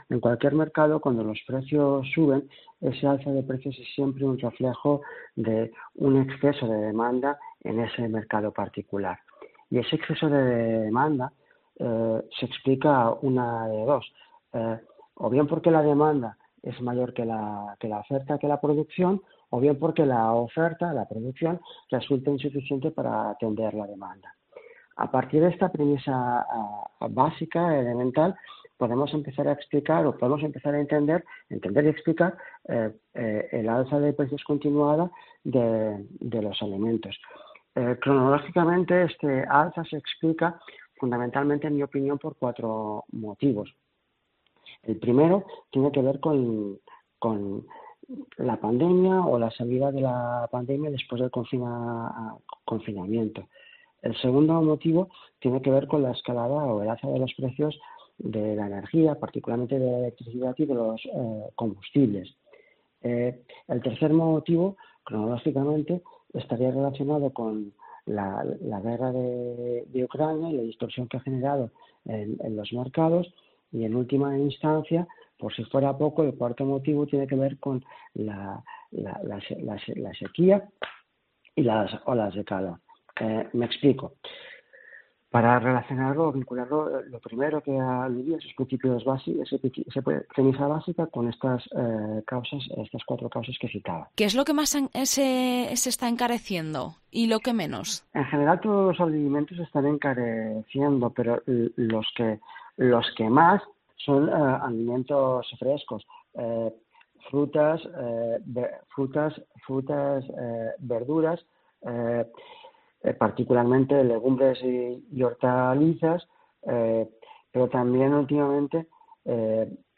Hablamos con un experto económico que explica los motivos por el que no paran de subir los precios de los alimentos
Entrevista